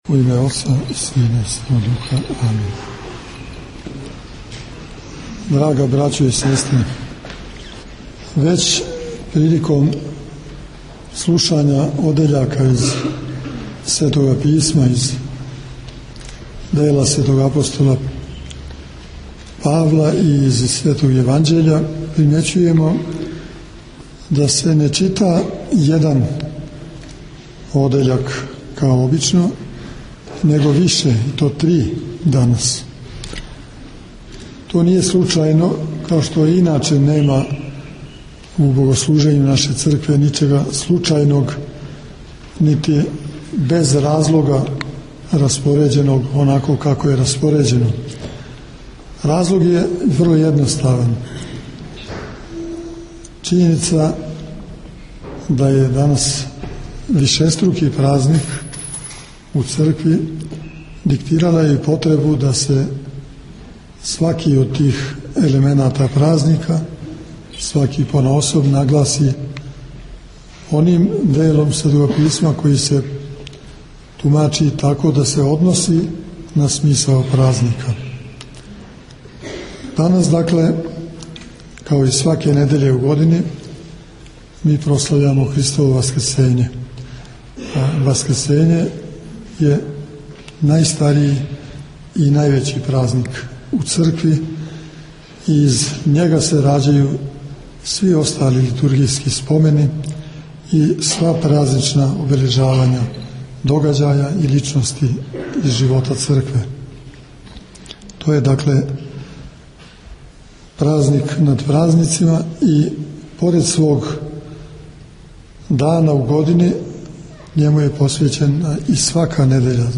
Беседа Епископа Иринеја на празник Светог Игнатија Богоносца у Врању
Поводом крсне славе Његовог Преосвештенства Епископа врањског Господина Пахомија, празника Светог Игњатија Богоносца, служена је света архијерејска Литургија у Саборном храму Свете Тројице у Врању, којом је началствовао Његово Преосвештенство Епископ бачки Господин др Иринеј, уз саслужење Епископа врањског Господина Пахомија и Епископа битољског Господина Марка.